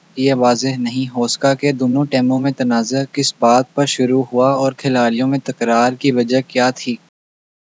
Spoofed_TTS/Speaker_02/14.wav · CSALT/deepfake_detection_dataset_urdu at main